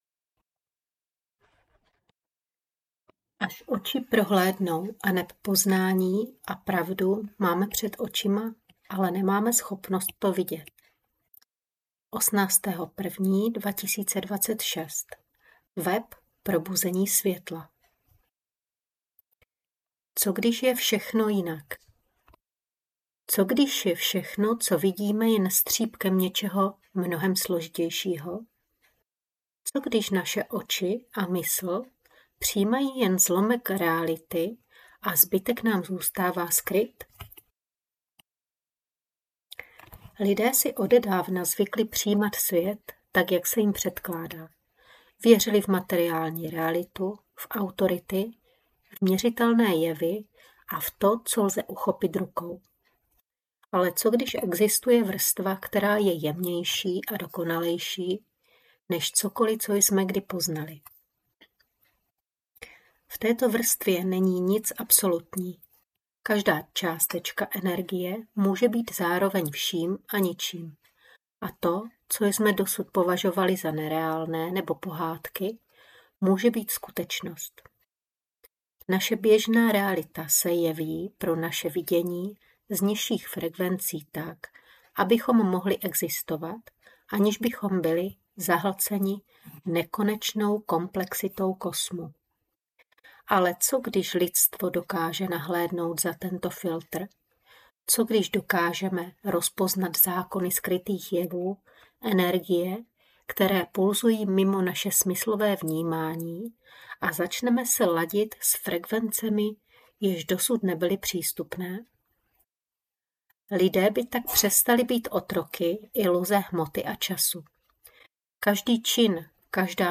STÁHNOUT Až oč...dnou.mp3 čtený text: 44 minut Co když je všechno, co vidíme, jen střípkem něčeho mnohem složitějšího?